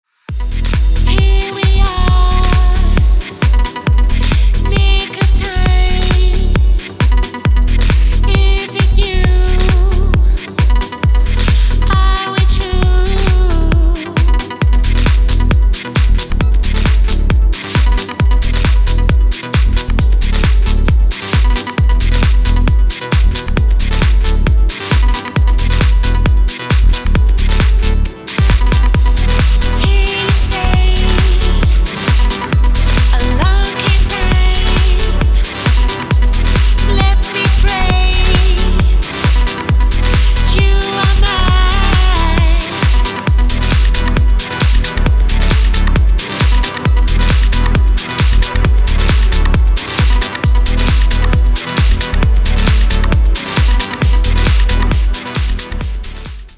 • Качество: 256, Stereo
женский вокал
dance
Electronic
EDM
электронная музыка
Trance